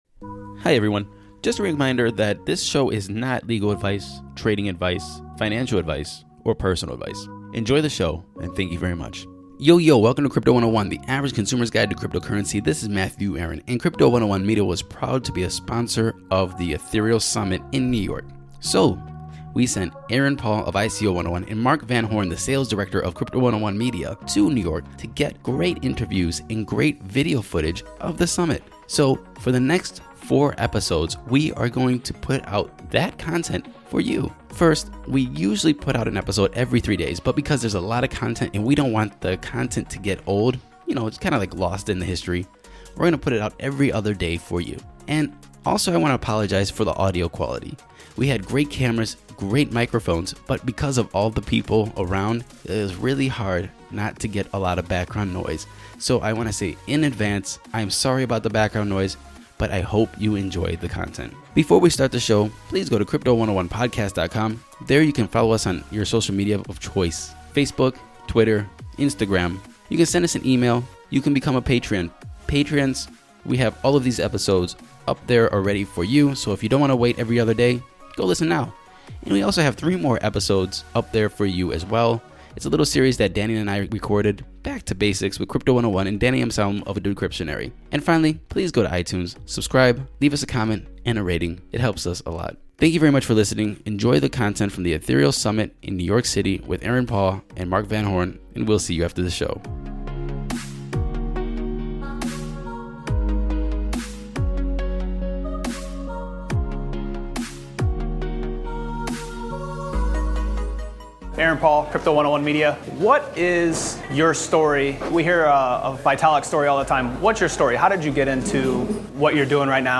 interview
*** this is not financial or legal advice*** CRYPTO 101 Media was a media sponsor for the Ethereal Summit in NYC and we want to take the next few episodes to share some of the content we gathered.